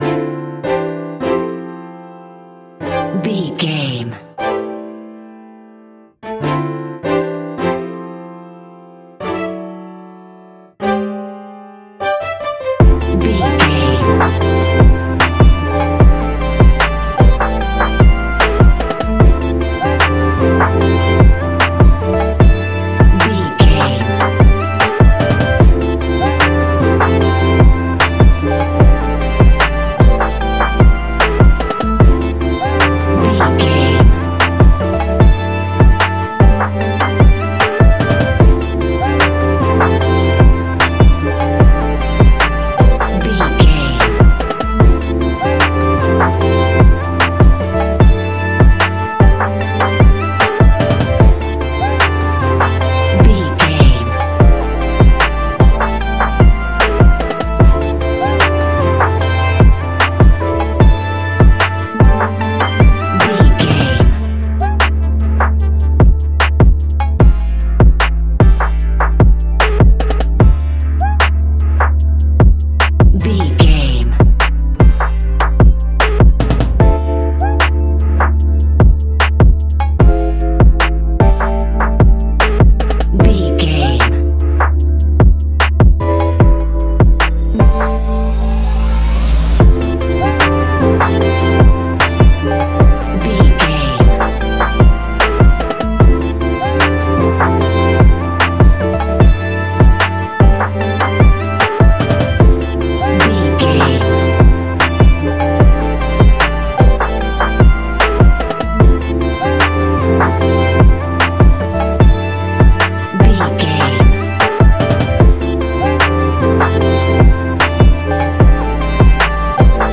Ionian/Major
D♭
Lounge
new age
chilled electronica
ambient